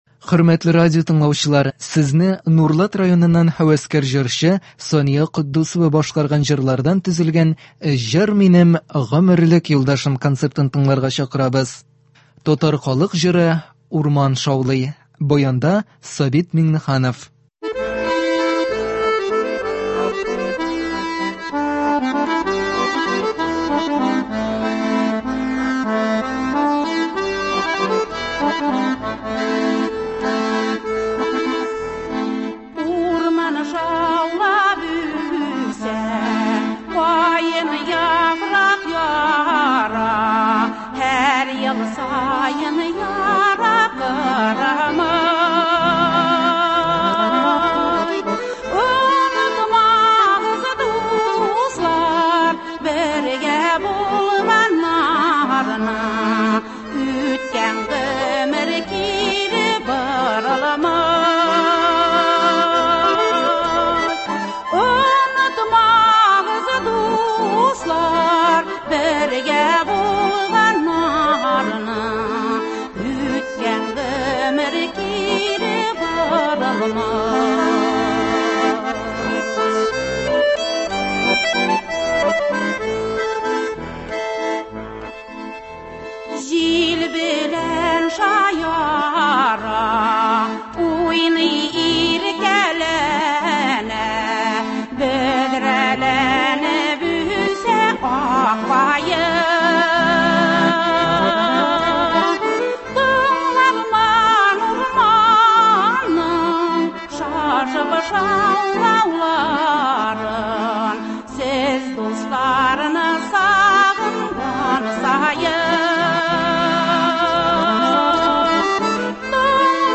Концерт (15.03.21)